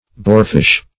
Boarfish \Boar"fish`\, n. (Zool.)